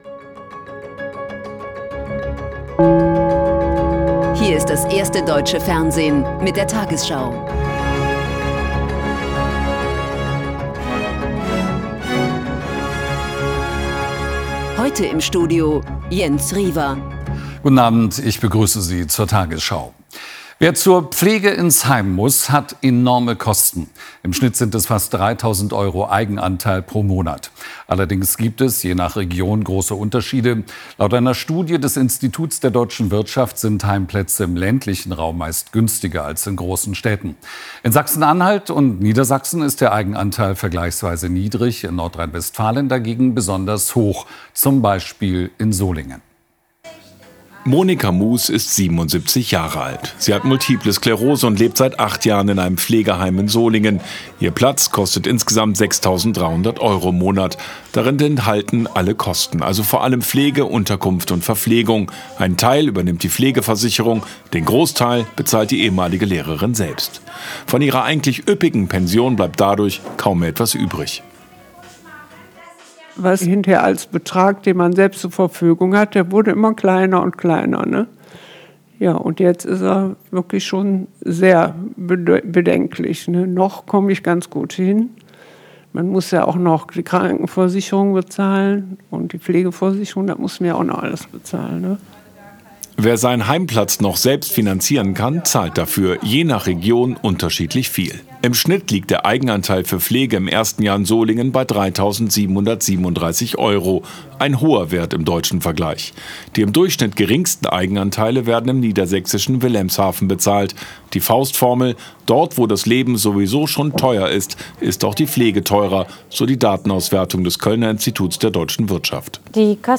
tagesschau 20:00 Uhr, 17.11.2025 ~ tagesschau: Die 20 Uhr Nachrichten (Audio) Podcast